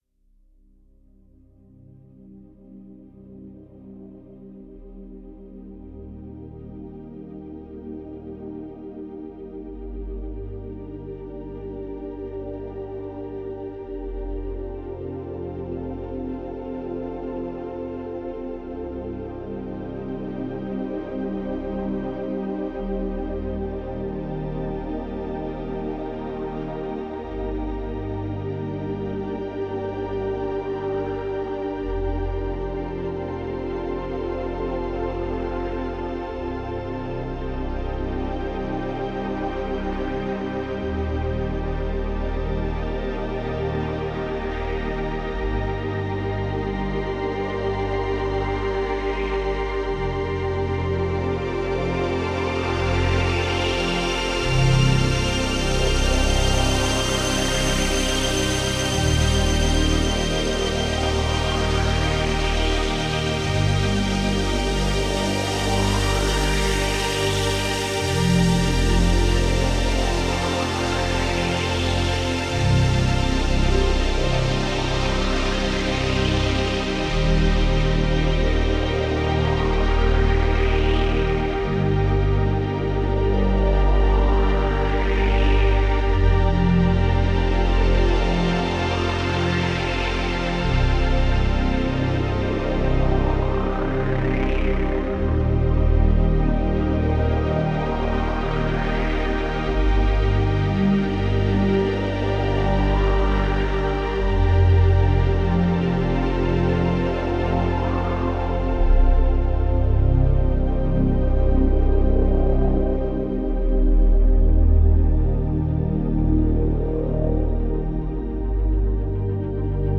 Genre: Electronic SpaceSynth.